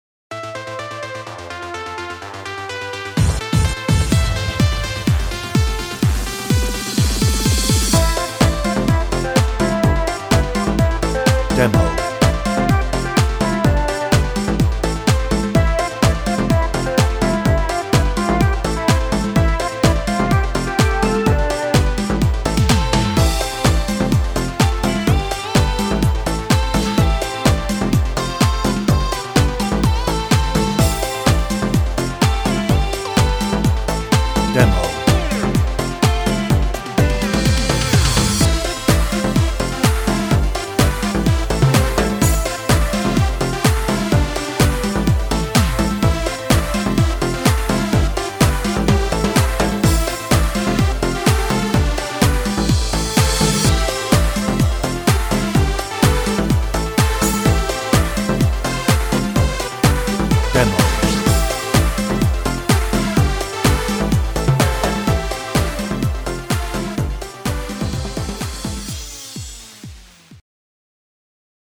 Hoedown - No ref vocal
Instrumental